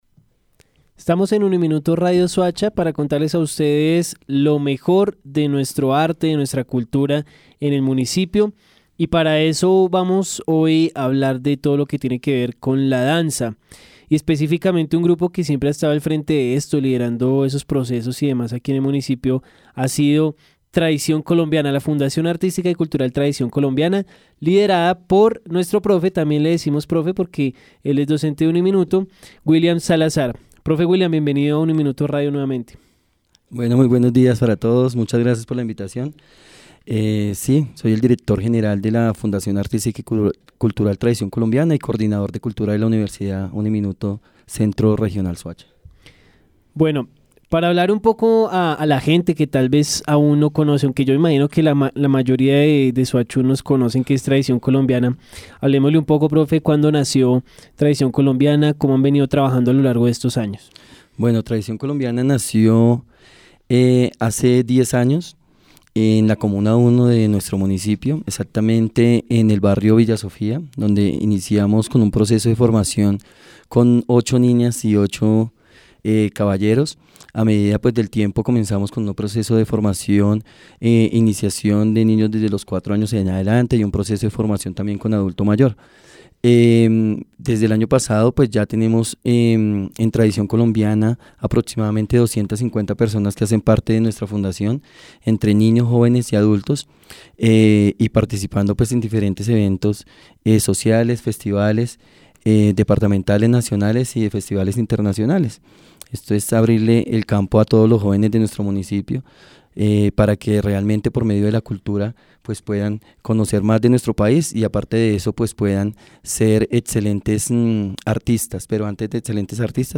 Entrevista-Tradición-Colombiana-1.mp3